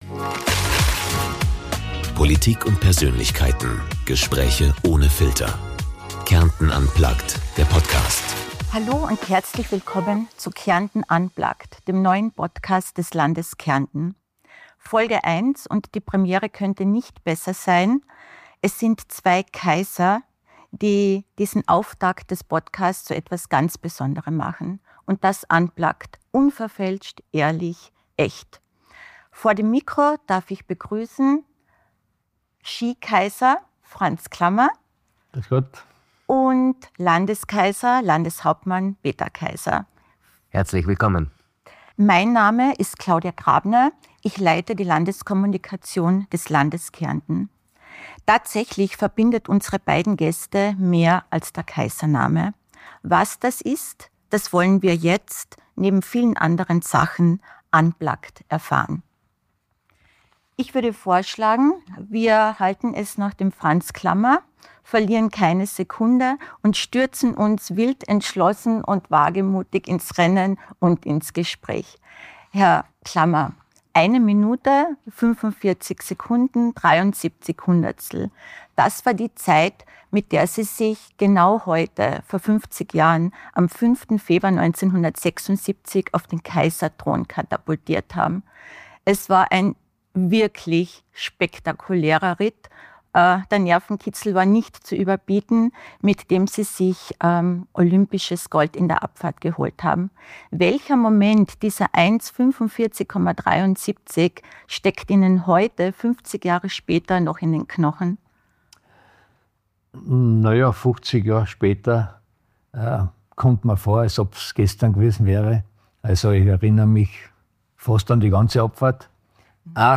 Ski-Ikone Franz Klammer und Landeshauptmann Peter Kaiser. Im Mittelpunkt stehen Klammers legendärer Olympia-Abfahrtslauf 1976 (1:45,73), der Umgang mit Druck, Mut und Bauchgefühl – und was diese Erfahrungen mit politischer Führung gemeinsam haben. Dazu: Freundschaft mit Rivalen, geerdet bleiben trotz Ruhm, Zukunft des Skisports und warum Kärnten für beide vor allem eines ist: Heimat.